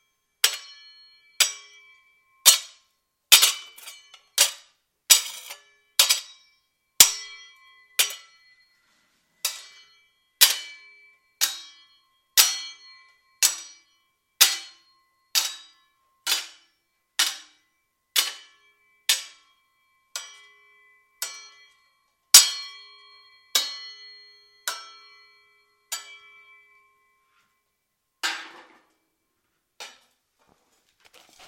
盾牌撞击的影响
夸张的旋风和强烈分层的冲击力，具有漂亮的子层和一些增加的木材/金属foley，以赋予其冲击和崩溃的感觉。
标签： 视频游戏 游戏 中世纪 战斗 盾击 幻想 分层 棍棒 福利 金属 装甲 SFX 打击 剑击 战士 影响
声道立体声